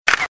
camera_click.mp3